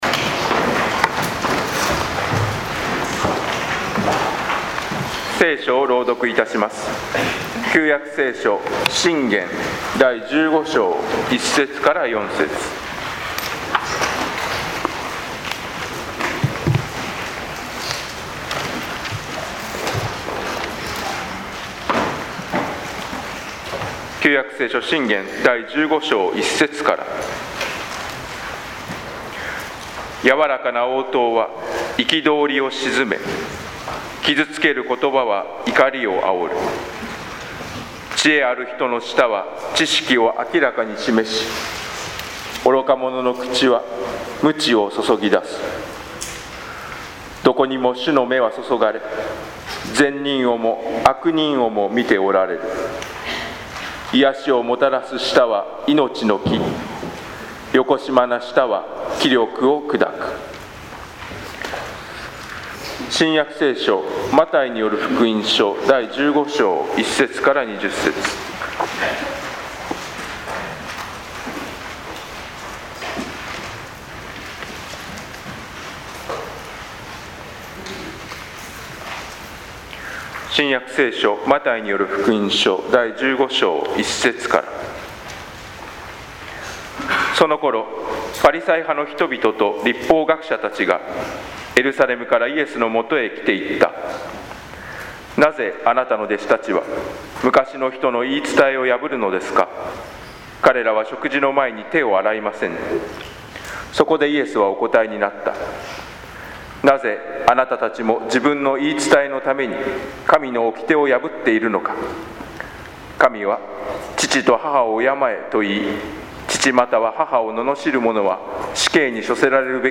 説 教